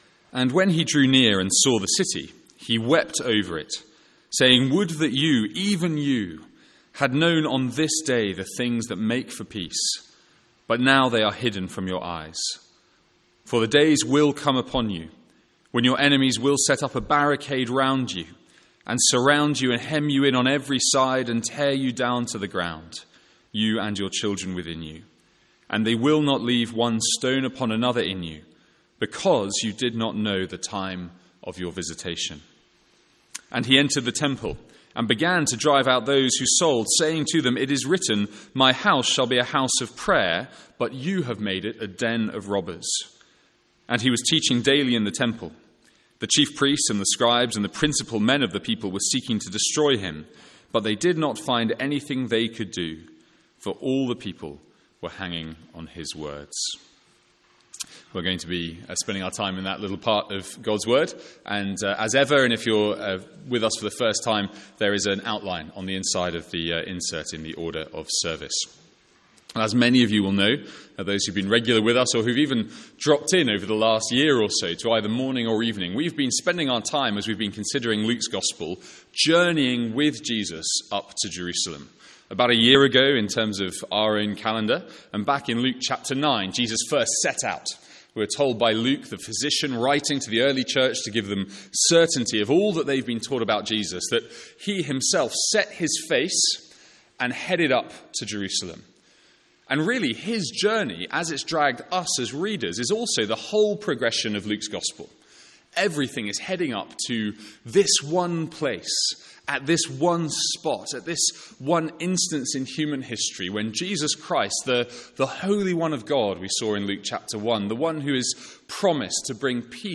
Sermons | St Andrews Free Church
From our evening series in Luke.